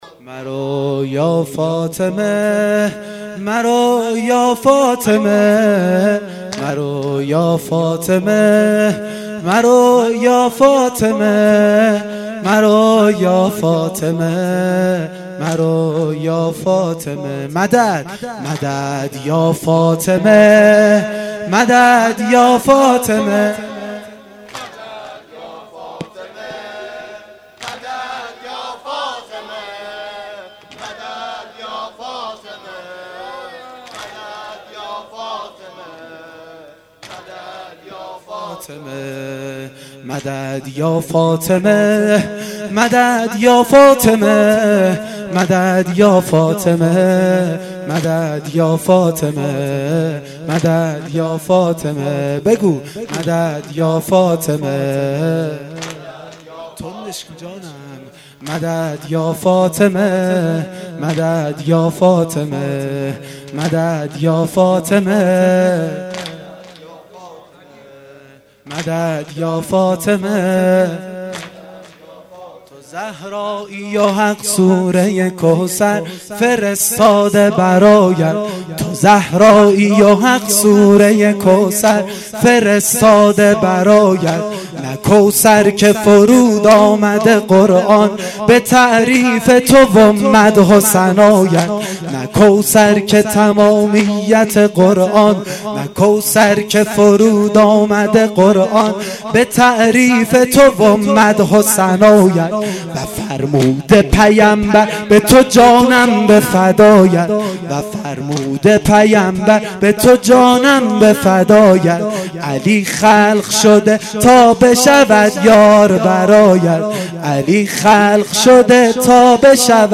واحد شب سوم فاطمیه دوم